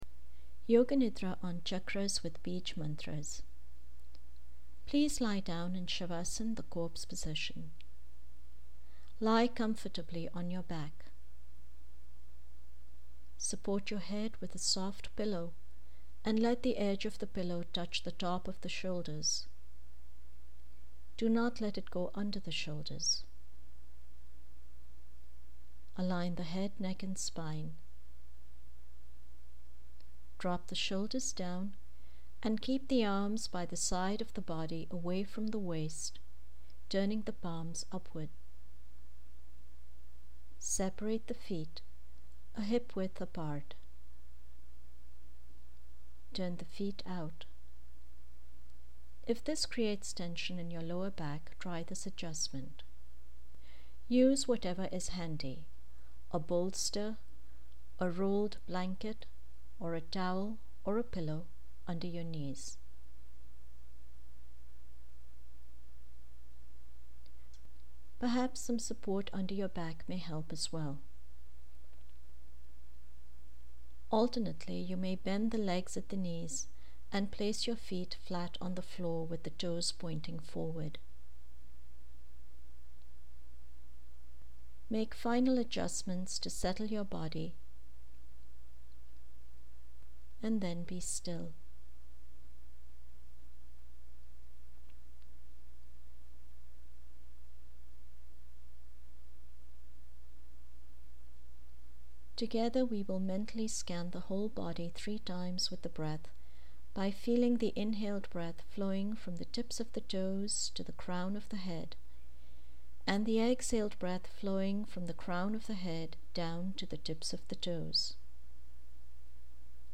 This Yoga Nidra is a guided yoga meditation to purify the body through chakras (energy centers) and their individual sound vibrations. The meditation systematically locates important chakras.
meditation-yoga-nidra-with-chakras-and-bija-mantras.mp3